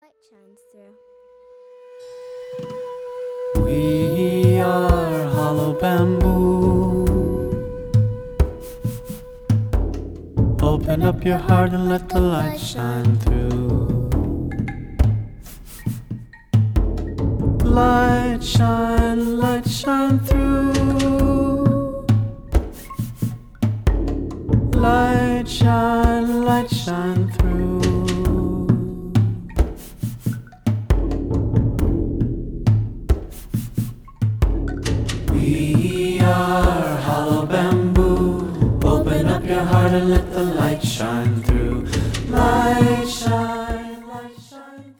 Funky Jewish music for the whole family!